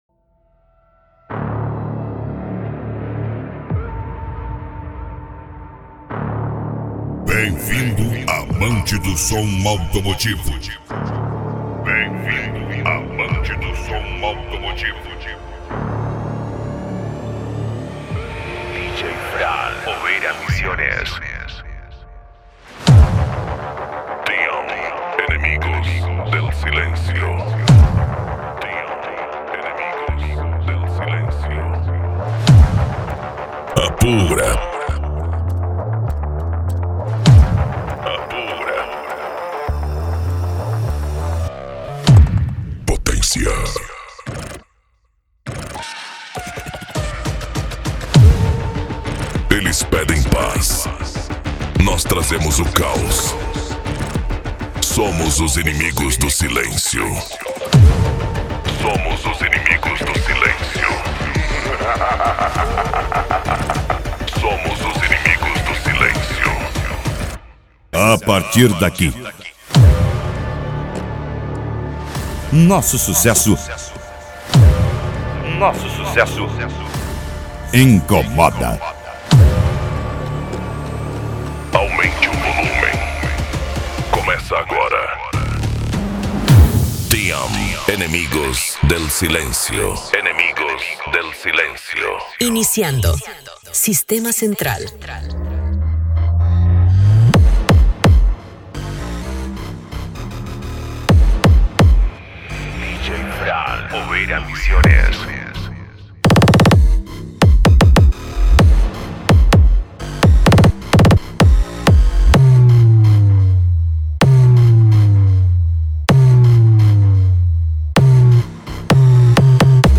Bass
Mega Funk
Remix